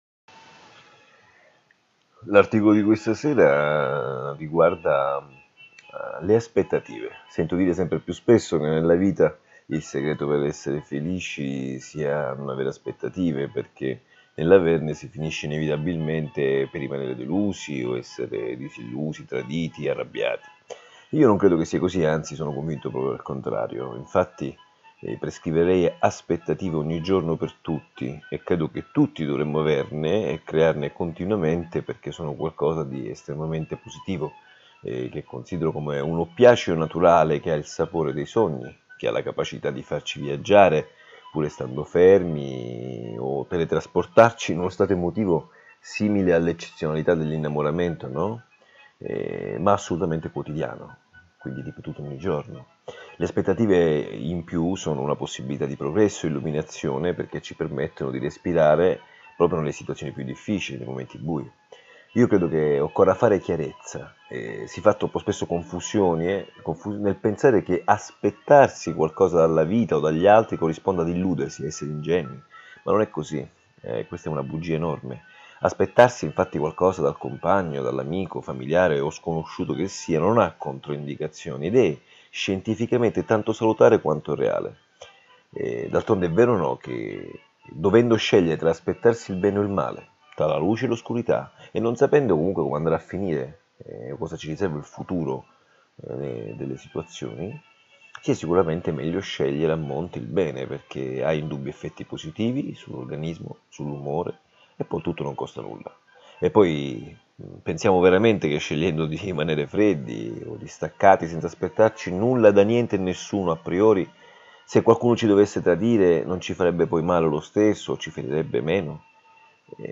6 MINUTI D’AUDIO Riproducono la riflessione dell’articolo a voce alta, perché abbiano accesso all’ascolto i ciechi, chi lavorando non ha tempo per leggere e quelli tra noi che pur avendo la vista sono diventati i veri Non Vedenti.